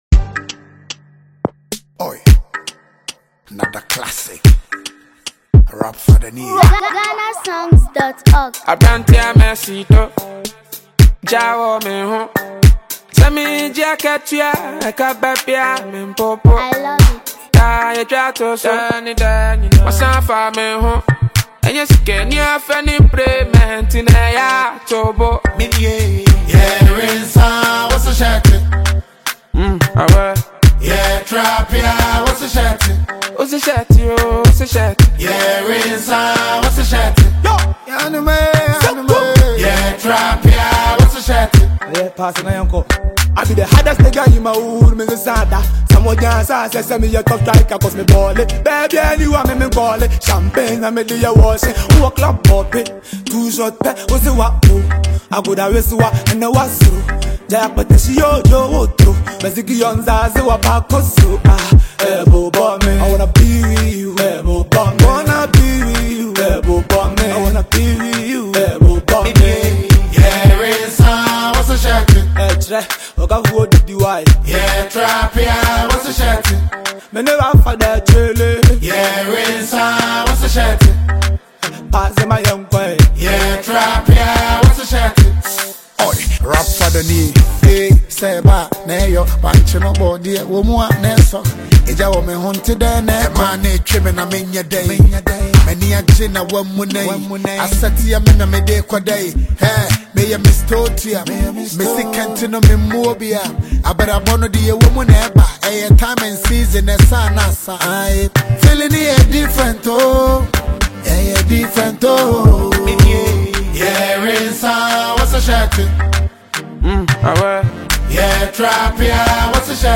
upbeat instrumental
With its lively rhythm and relatable themes